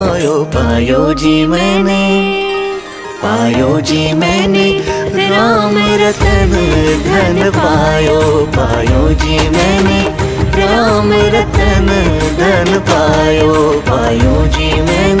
devotional music